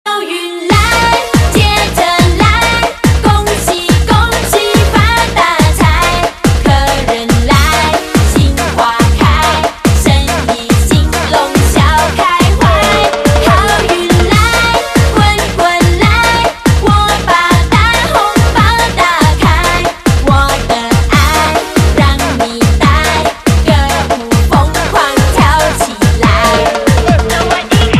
MP3铃声